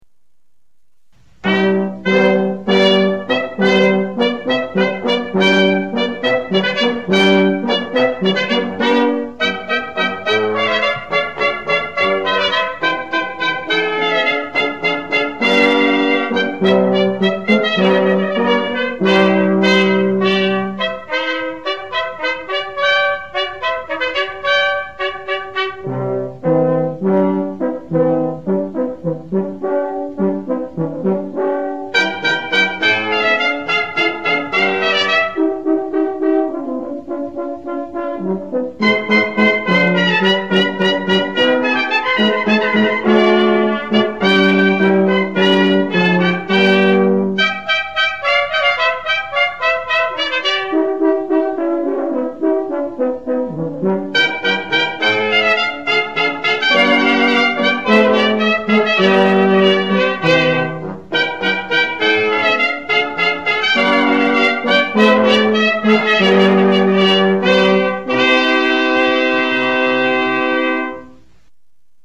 Click on the underlined titles below to hear the Masterpiece Brass Quintet or visit Hear us!
Hornpipe Allegro Maestoso, Air & other "Watermusic" selections